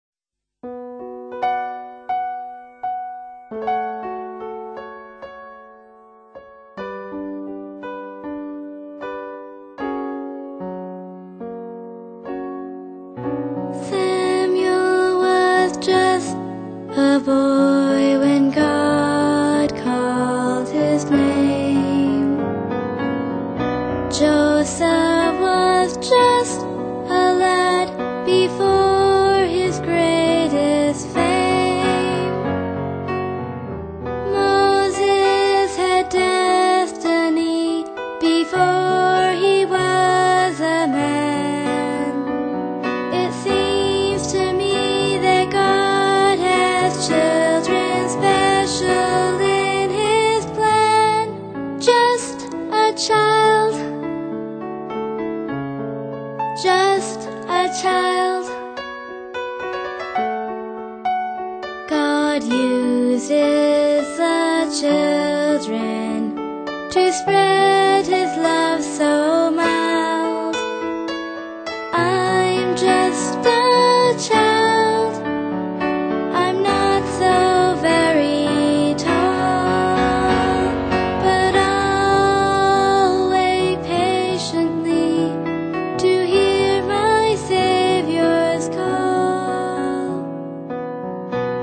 Heaven's Harmony Children's Music is music education for children aged 4-17 using Christian themed songs that the children sing and compose themselves